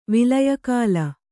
♪ vilaya kāla